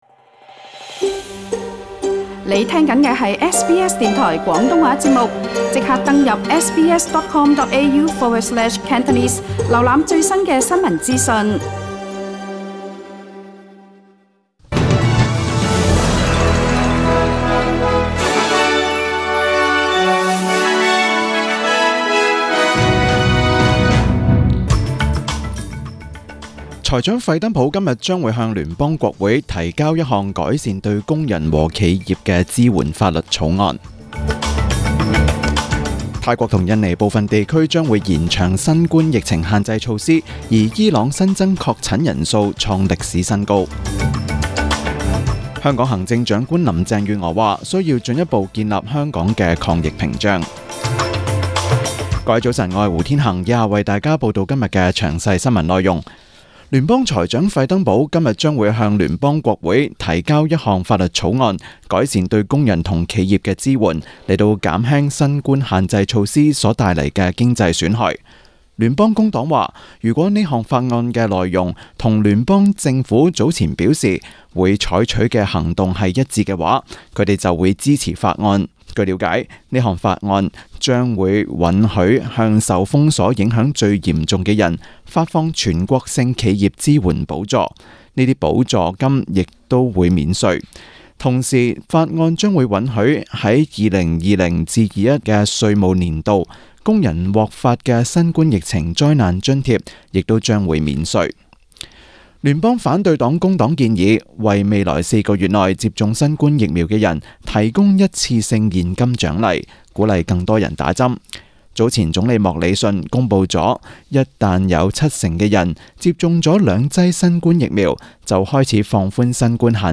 SBS 中文新聞 （八月三日）